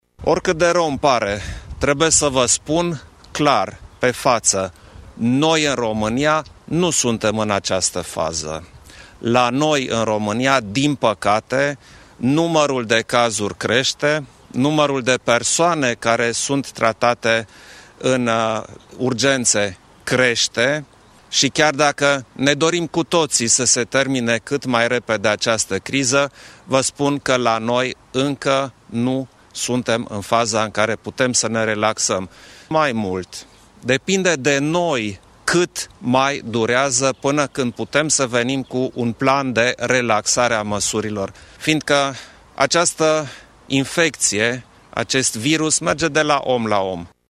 Șeful statului, Klaus Iohannis, a anunțat în urmă cu puțin timp că, deși alte state se pregătesc să relaxeze măsurile restrictive, România nu a ajuns încă în faza în care se poate relaxa: